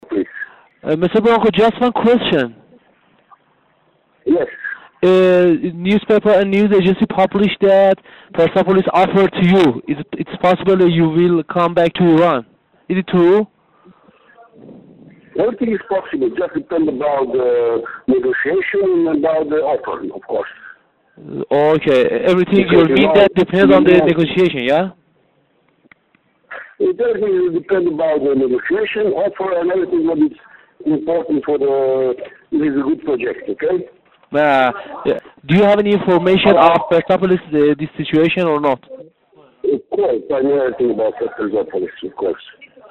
به گزارش مشرق، پس از اعلام این موضوع، خبرهای ضد و نقیضی برای تکذیب شدن این خبر منتشر شد که بر همین اساس و به منظور تنویر افکار عمومی و مشخص شدن صحت و سقم این خبر، خبرگزاری فارس فایل صوتی بخشی از این گفت و گو را منتشر کرد که در این قسمت برانکو در پاسخ به سؤال خبرنگار در خصوص اینکه آیا از شرایط پرسپولیس آگاه است، با اطمینان خاطر می‌گوید: البته.